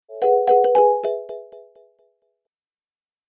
notification-loan.mp3